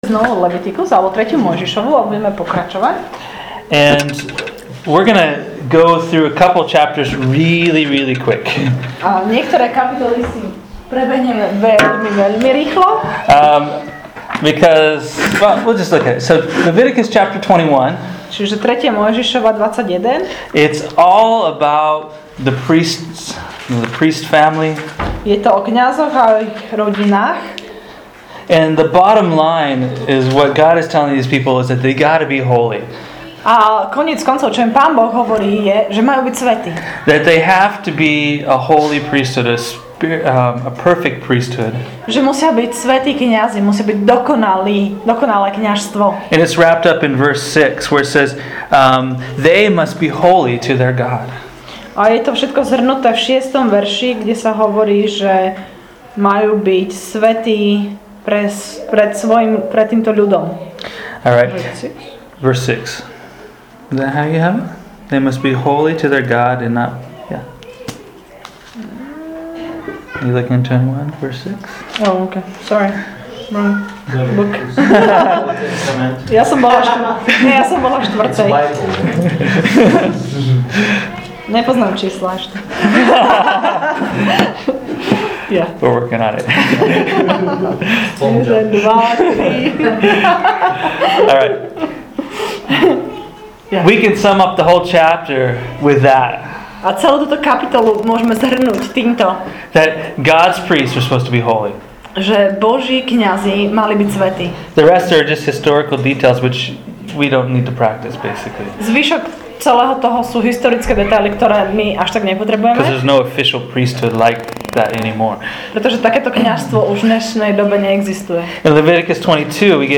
The dramatic & dynamic events of Jesus’ last days took place within the backdrop of the Passover… a joyous celebration of freedom. Enjoy this teaching from Leviticus 21-23 entitled: ‘Freedom Feast”.